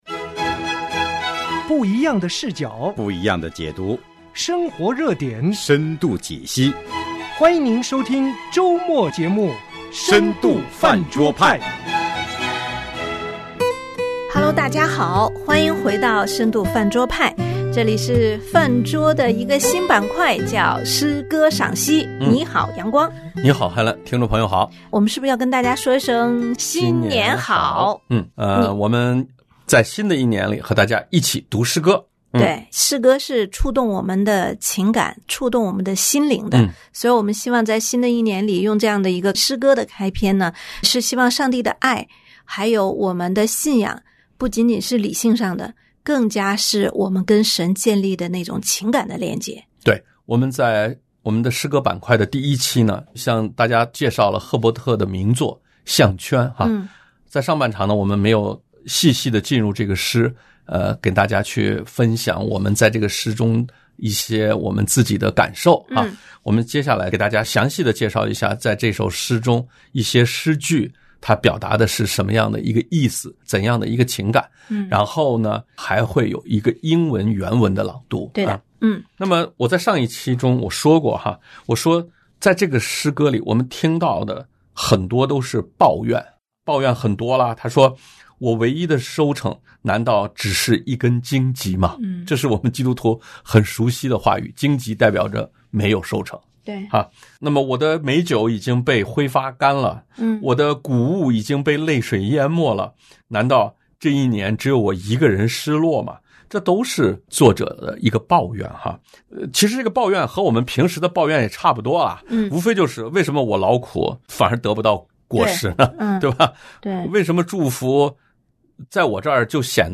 信仰里最真实的一刻：我拍桌子，神叫我孩子──乔治·赫伯特诗歌朗读与神学默想（2）